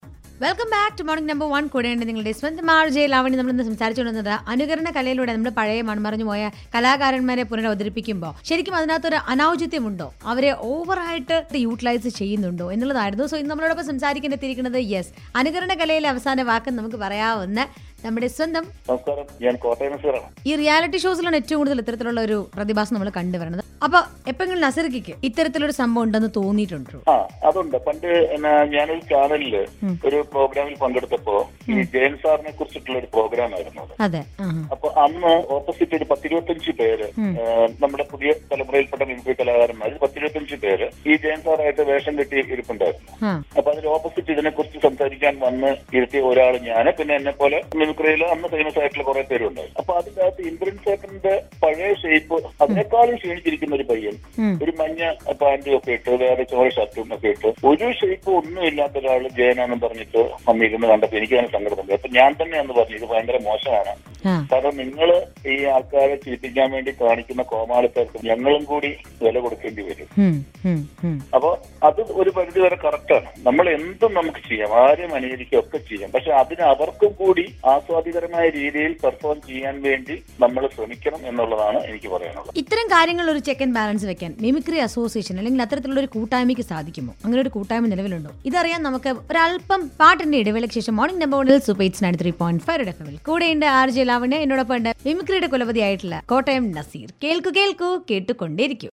Mimicry